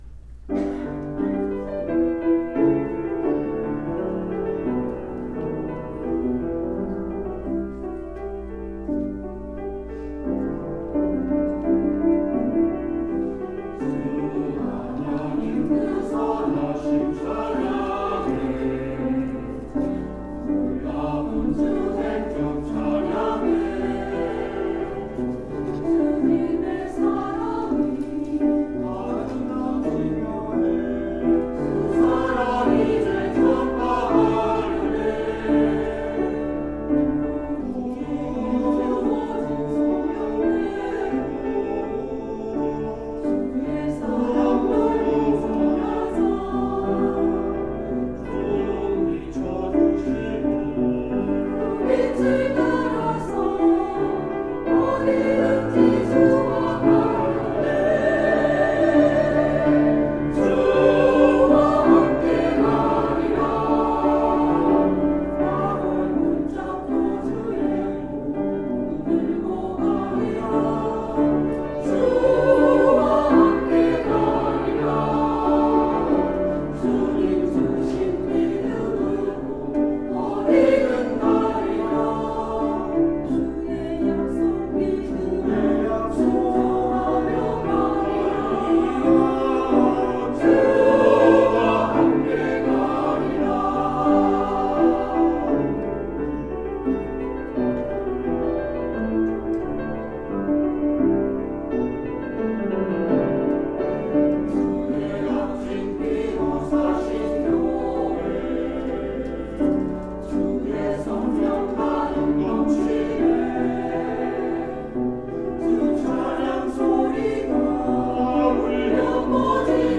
찬양대
[주일 찬양] 주와 함께 가리라